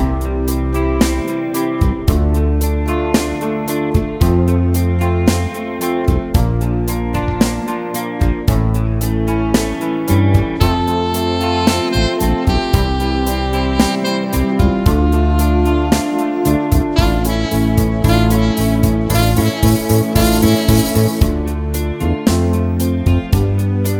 Full Version Pop (1980s) 4:46 Buy £1.50